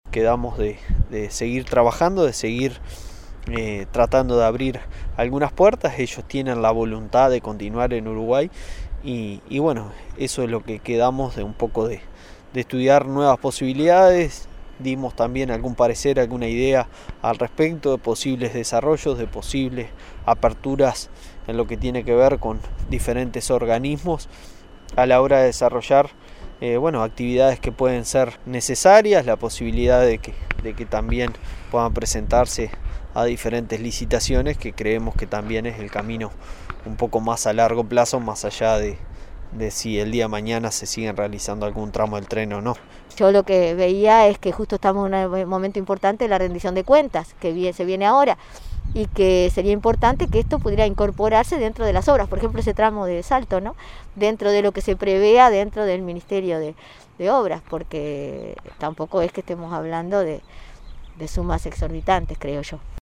Diputado nacionalista, Álvaro Rodríguez Hunter y la senadora frenteamplista, Amanda Della Ventura: